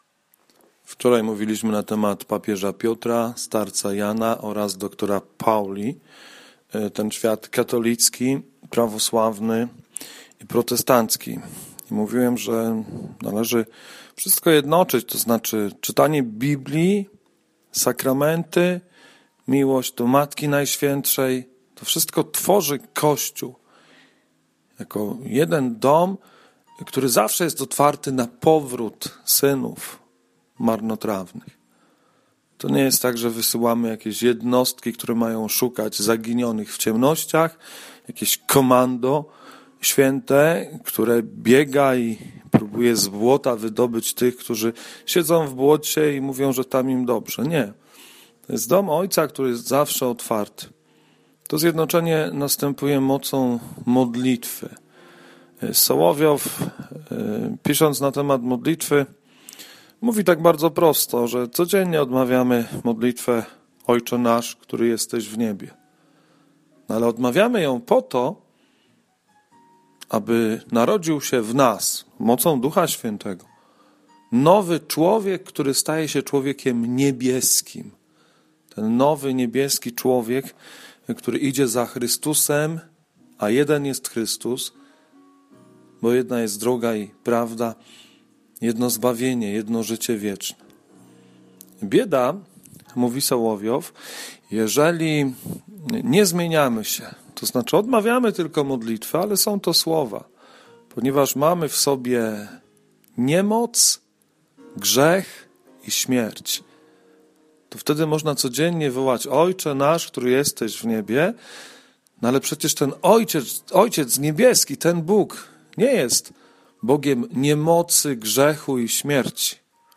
Komentarz do Ewangelii z dnia 23 stycznia 2018